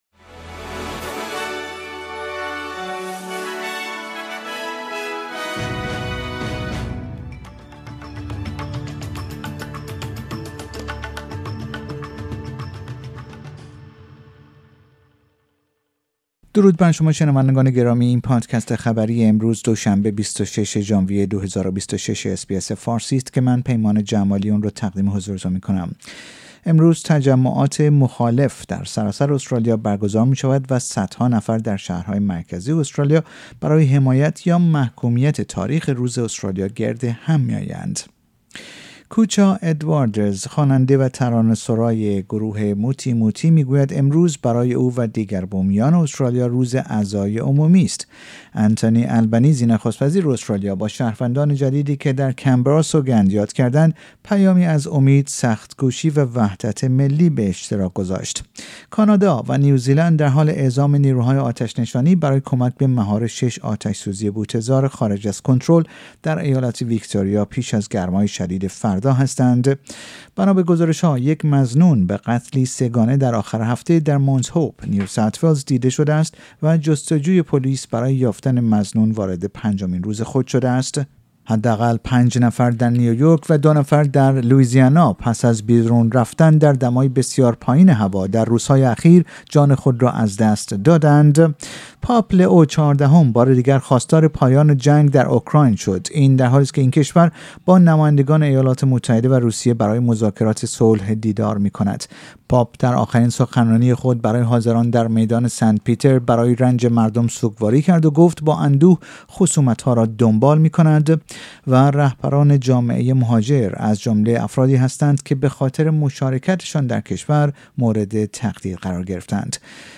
در این پادکست خبری مهمترین اخبار روز دوشنبه ۲۶ ژانویه ۲۰۲۶ ارائه شده است.